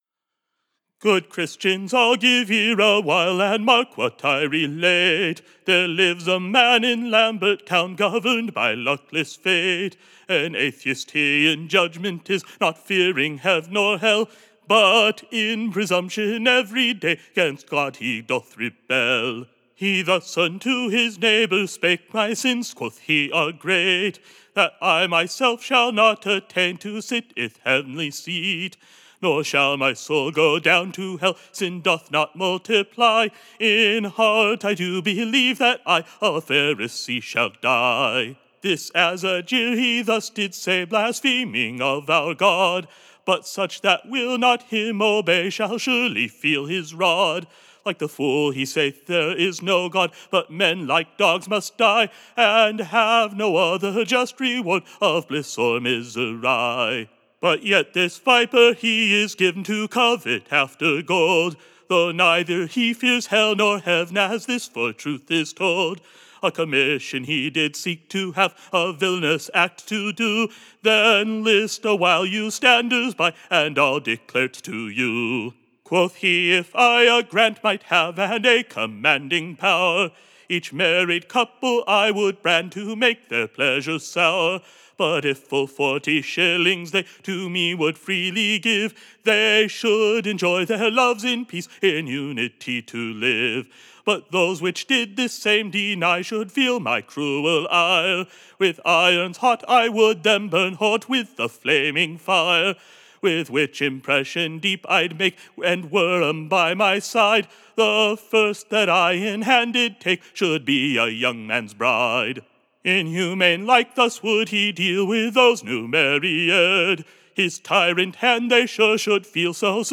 Recording Information Ballad Title [?]
Tune Imprint To the tune of, Jesper Cunninghams, or brave Lord Willoby.